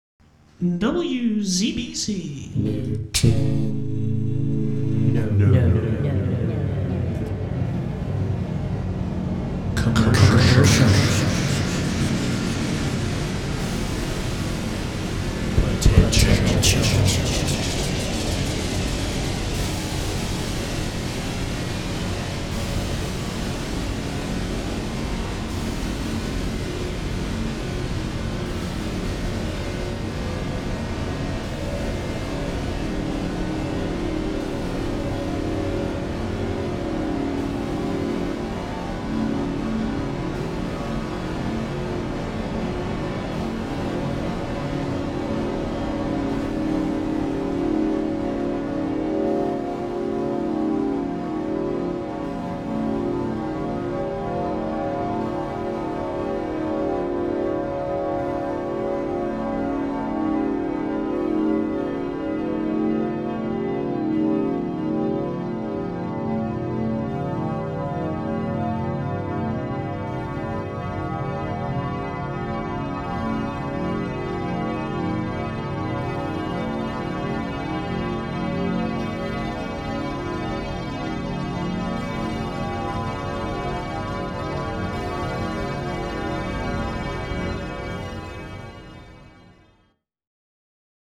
NCP Cascade Legal ID
NCP_drone NOT LEGAL.mp3